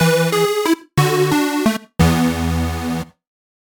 Jingle_Lose_00
8-bit 8bit Death Game Jingle Lose Nostalgic Old-School sound effect free sound royalty free Gaming